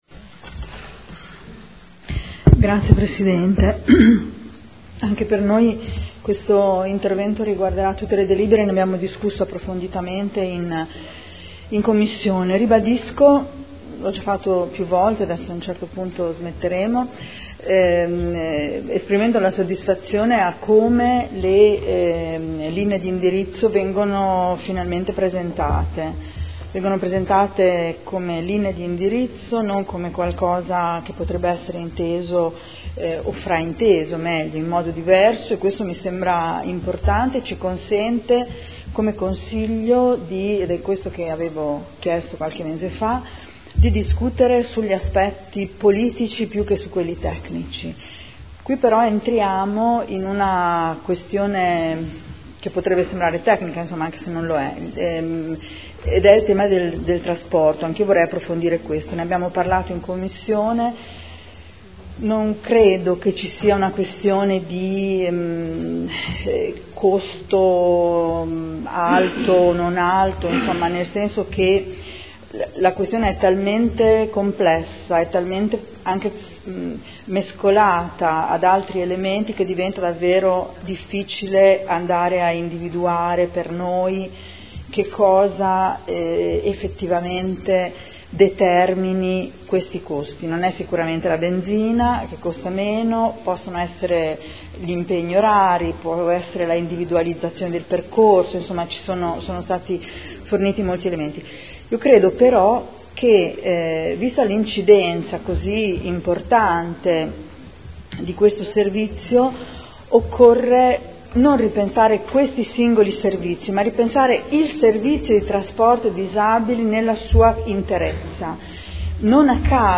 Seduta del 30/03/2015. Linee di indirizzo per la gestione di progetti socio-occupazionali presso centri o laboratori di piccolo assemblaggio rivolti a disabili adulti. Dibattito